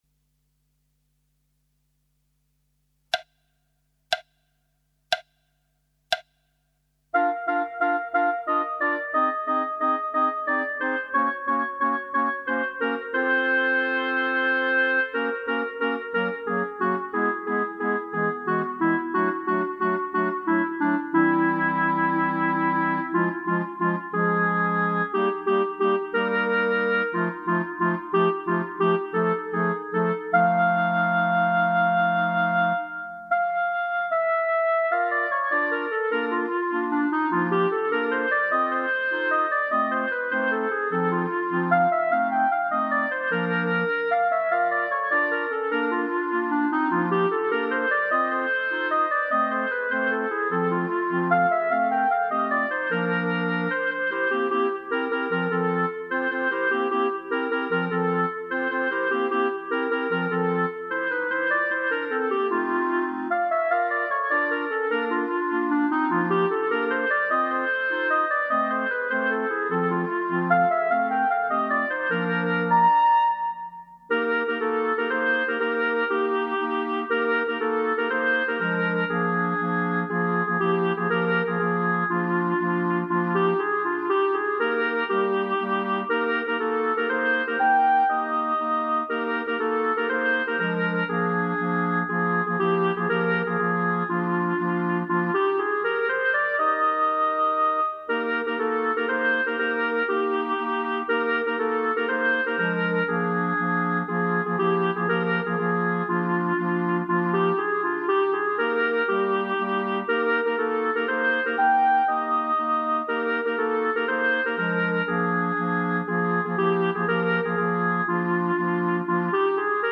minus Bass Clarinet